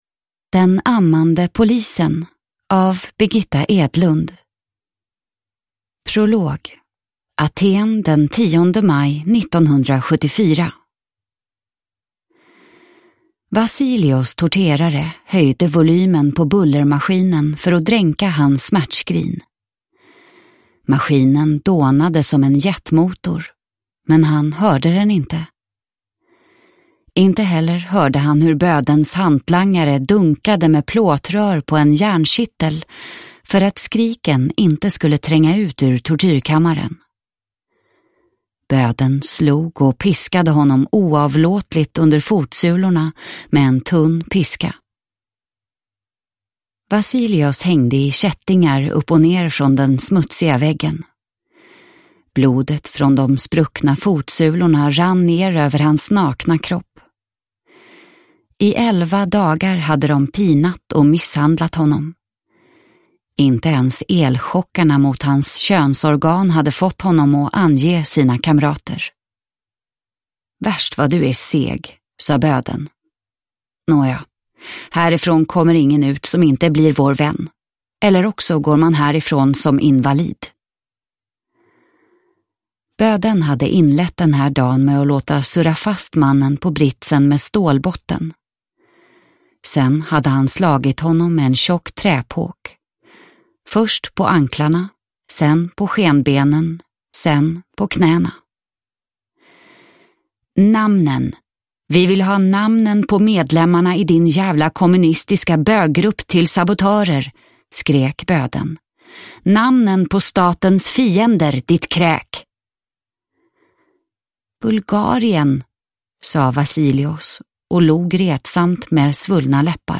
Den ammande polisen – Ljudbok